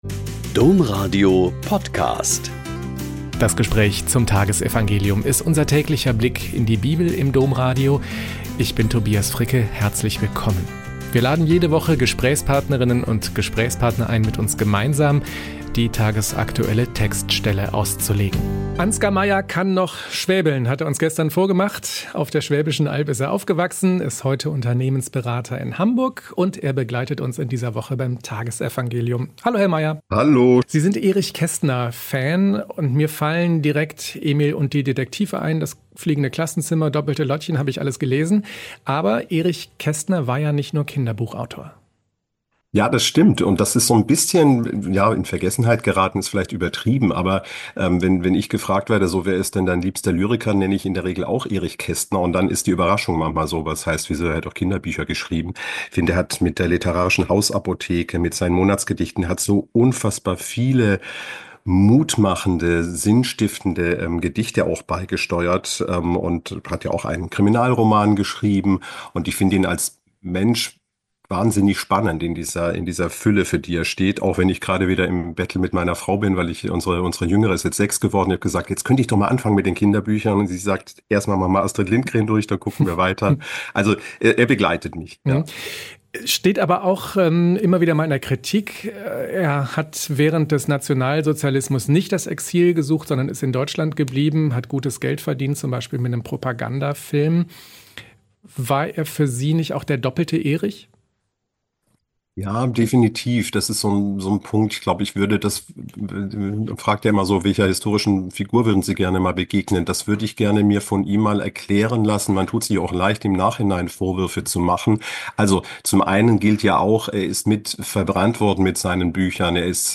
Lk 5,12-16 - Gespräch